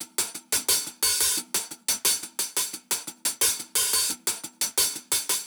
HIHAT_TELLEM.wav